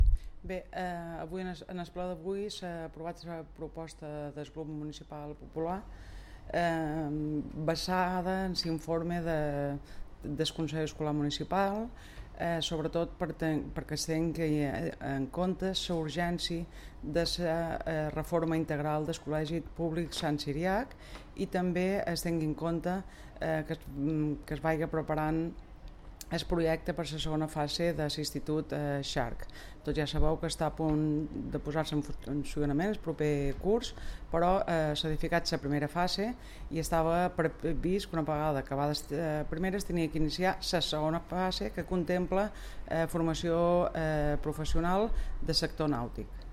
DECLARACIONS (mp3)
Ana Costa (regidora d'Educació): Col·legi Sant Ciriac